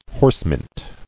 [horse·mint]